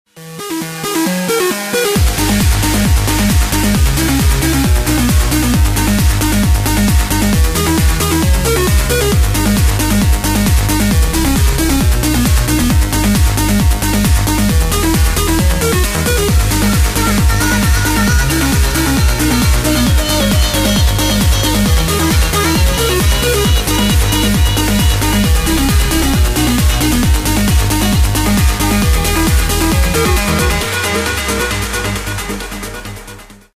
• Качество: 128, Stereo
громкие
dance
Electronic
EDM
электронная музыка
без слов
progressive trance